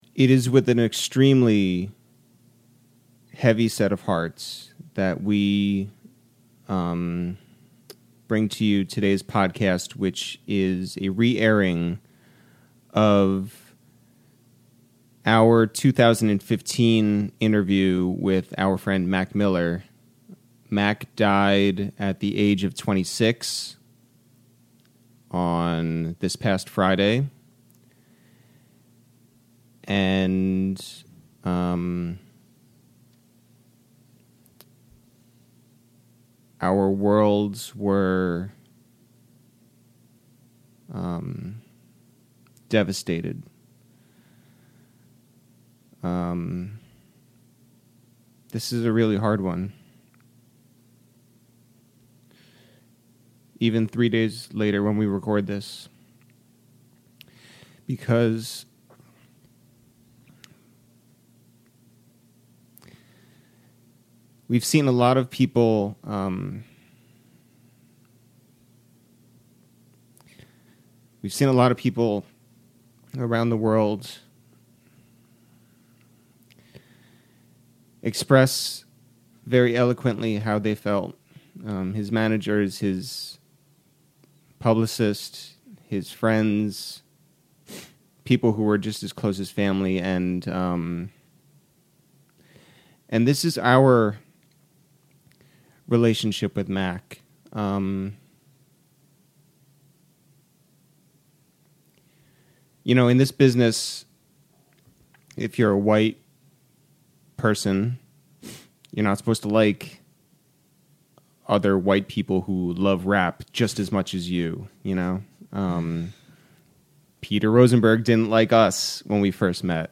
Please enjoy the words and wisdom and wonder of the legend Mac Miller from our 2015 episode, recorded in Brooklyn.